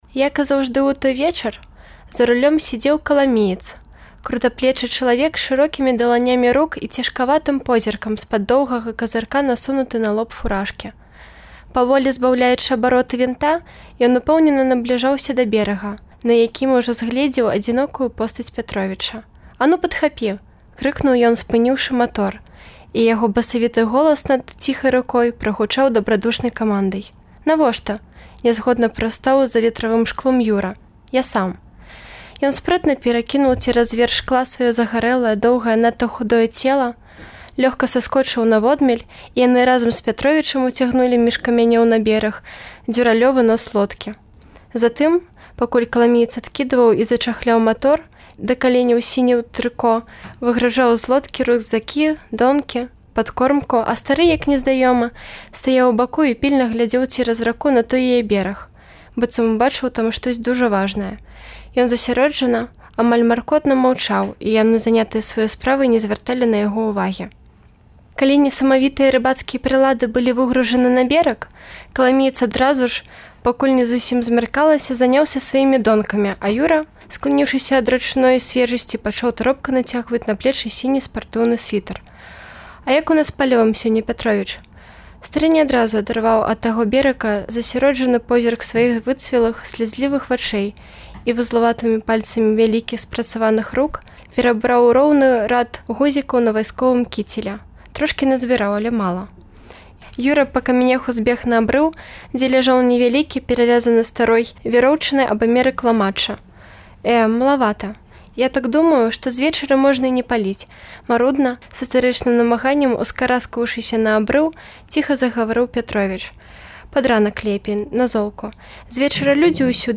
Штодня ў чэрвені выпускнікі Беларускага гуманітарнага ліцэю (цяперашнія і колішнія) чытаюць радкі з улюблёных быкаўскіх твораў.